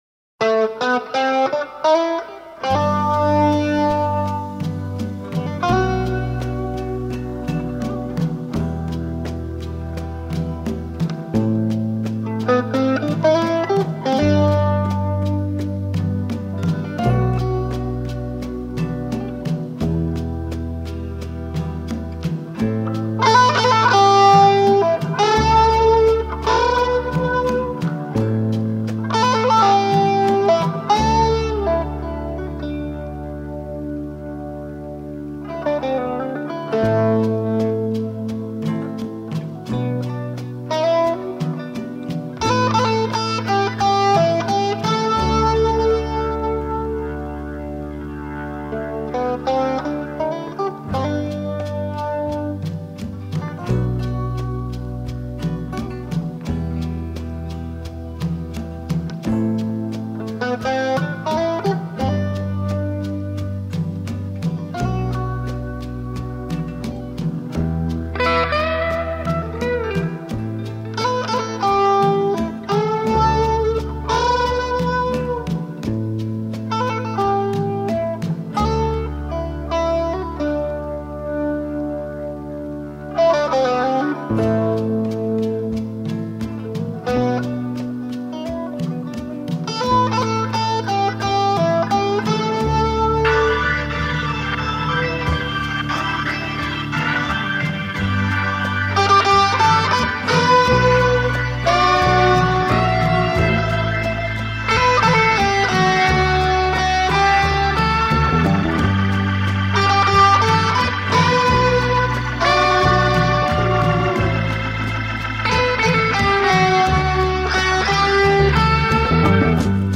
expressive instrumental ballad nostalgic!